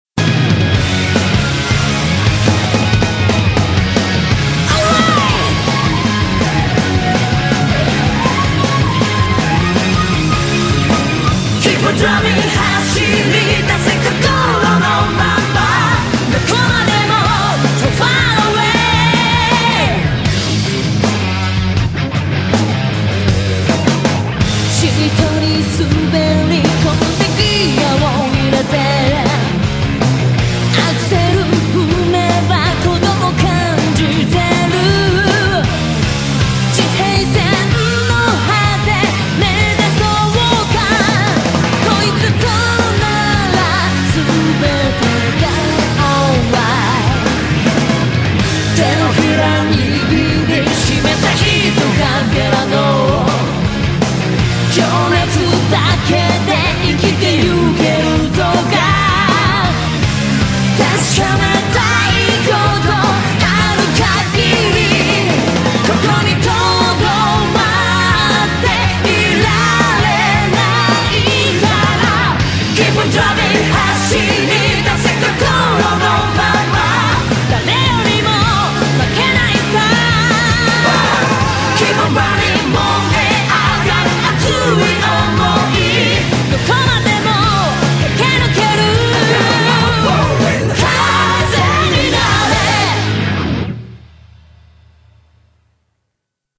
BPM160-160
Audio QualityCut From Video